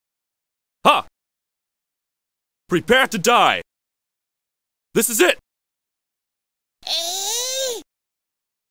Disclaimer: this doesn’t represent the audio as you’d hear it in the game per se. It does occur in the game; just alongside music and sound effects and not strictly in the order presented here.
Does the crying noise represent the same character, or is that other game audio?